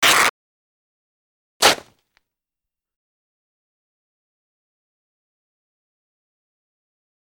Cloth Tears Fast Dull Sound
household
Cloth Tears Fast Dull